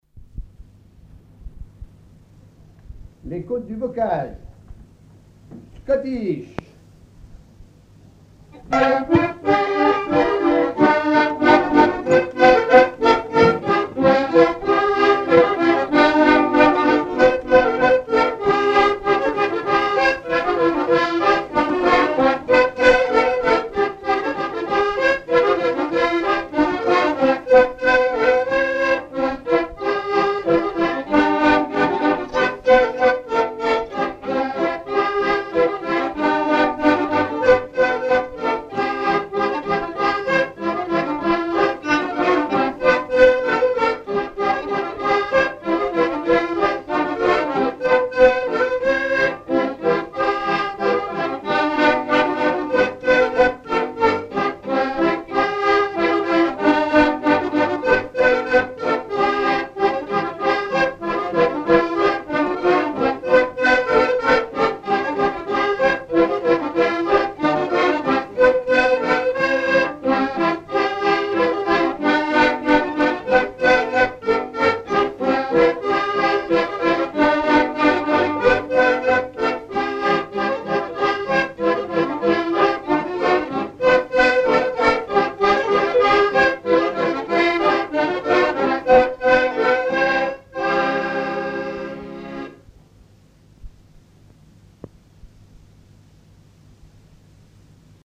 Rochetrejoux
Chants brefs - A danser
danse : scottich trois pas
Pièce musicale inédite